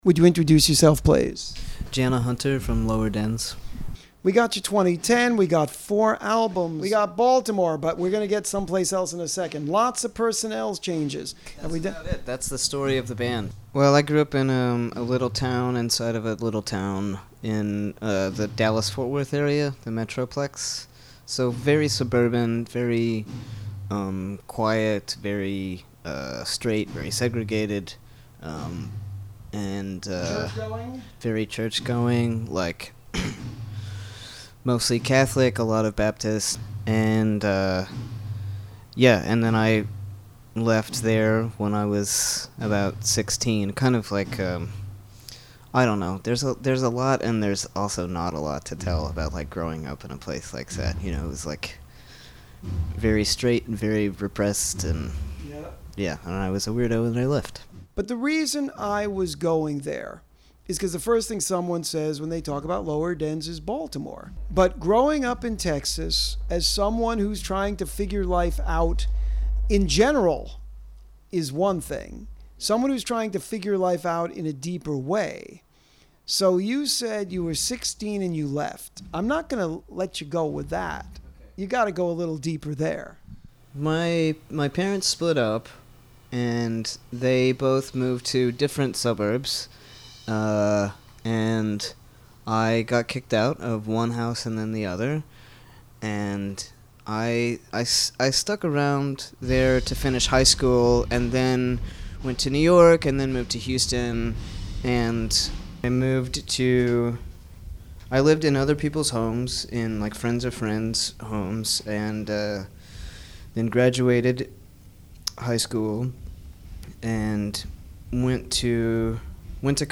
Lower Dens LISTEN TO THE INTERVIEW